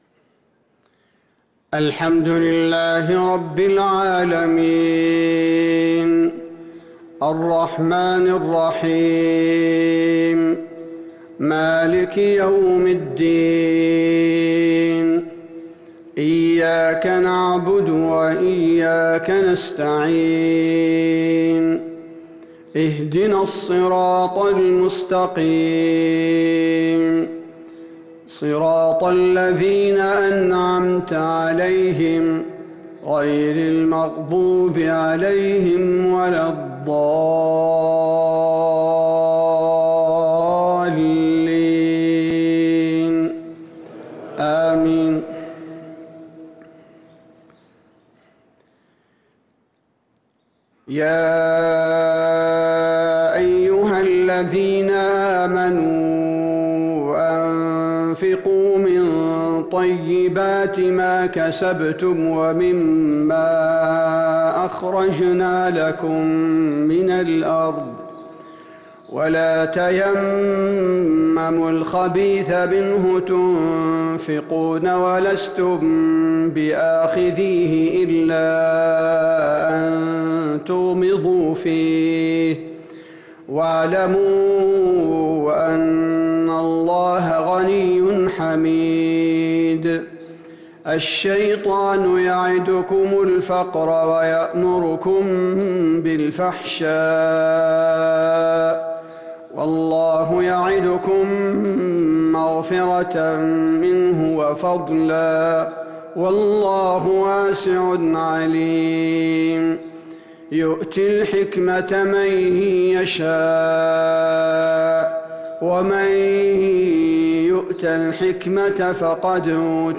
صلاة الفجر للقارئ عبدالباري الثبيتي 9 صفر 1443 هـ
تِلَاوَات الْحَرَمَيْن .